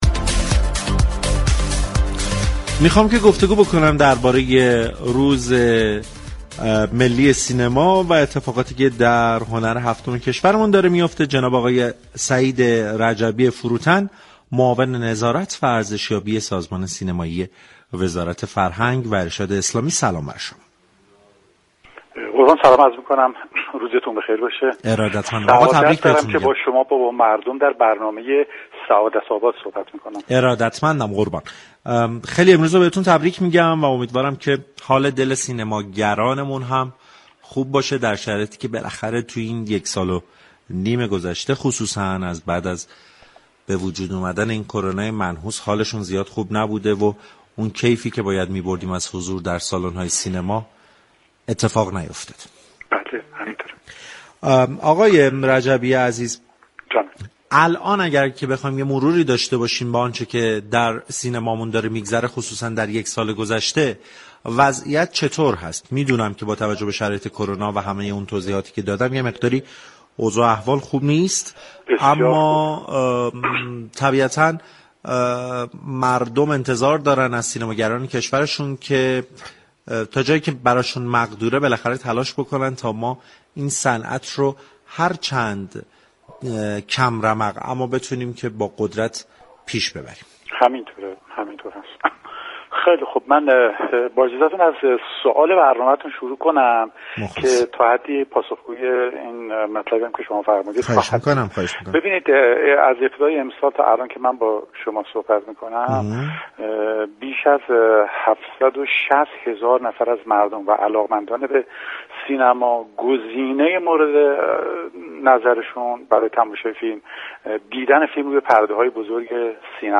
به گزارش پایگاه اطلاع رسانی رادیو تهران، 21 شهریور در تقویم ملی كشورمان به نام روز ملی سینما ثبت شده است در همین راستا سعید رجبی فروتن معاون نظارت و ارزشیابی سازمان سینمایی كشور با برنامه سعادت آباد رادیو تهران گفتگو كرد.